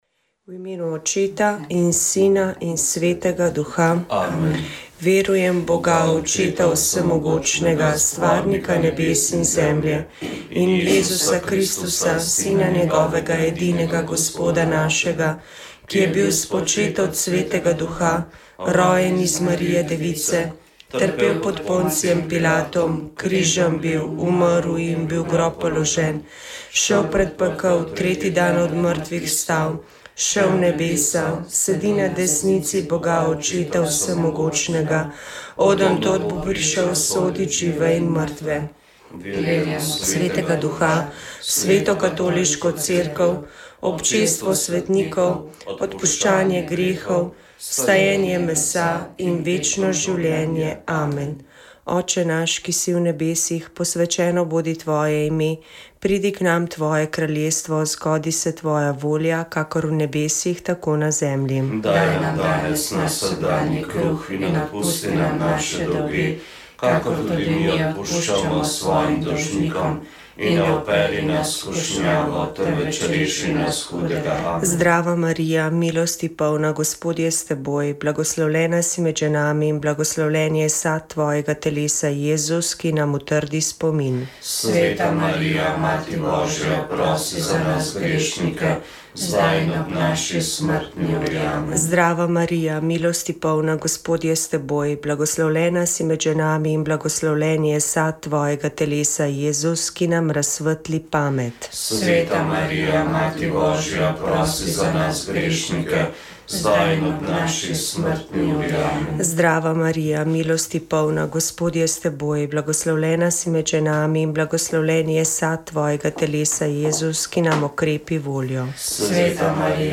Pogovor o VEČ ...